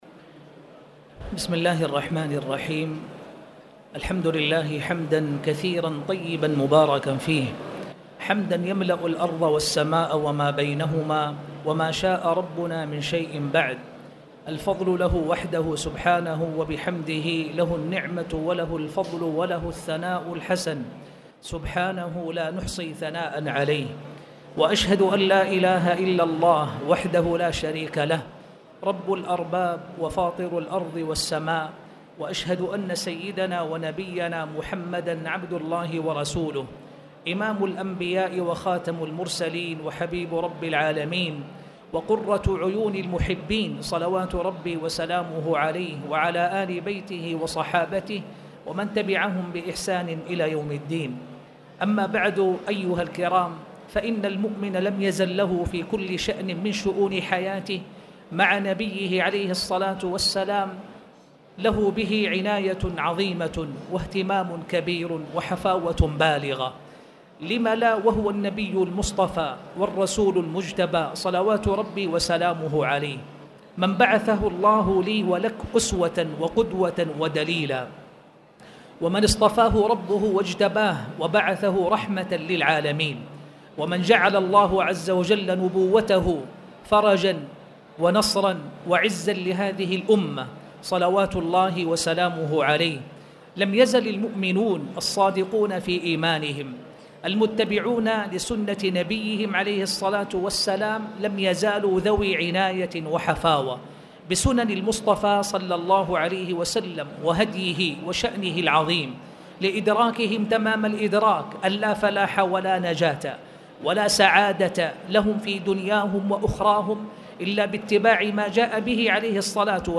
تاريخ النشر ١٤ ربيع الثاني ١٤٣٨ هـ المكان: المسجد الحرام الشيخ